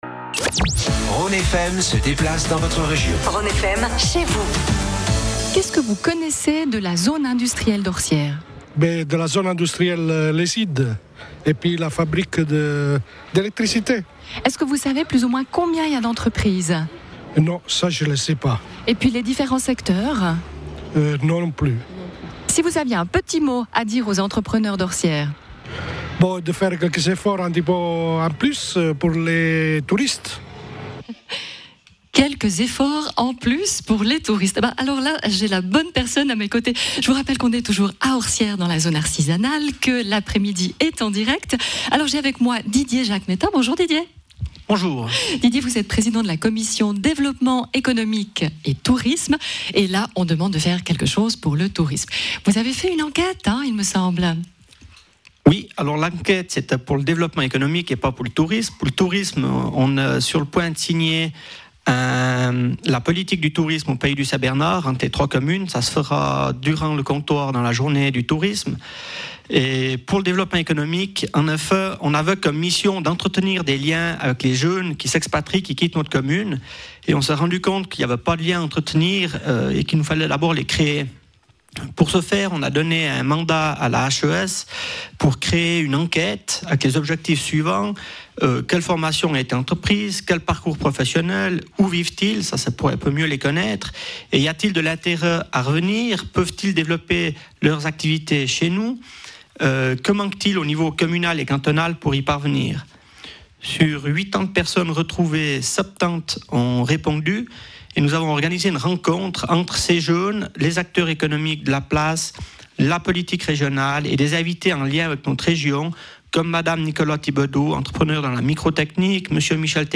Après une présentation de sa zone industrielle par le Président de commune, les interviews en direct de plusieurs entrepreneurs vous permettront de mieux connaitre une commune et son économie !
Interview du conseiller communal en charge du développement économique et tourisme, M.